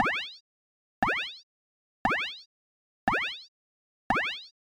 laser